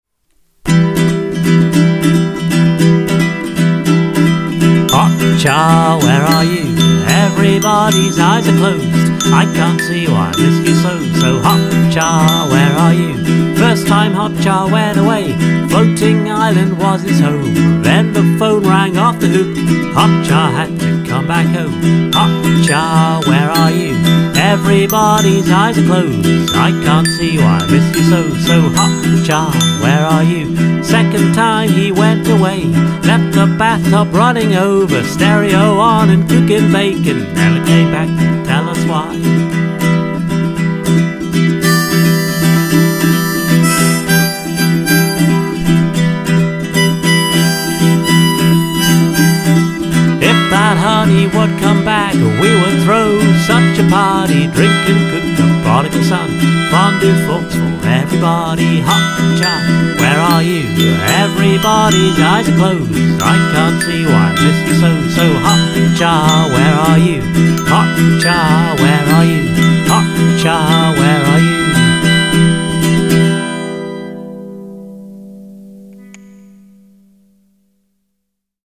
Is this the first outing for the glockenspiel?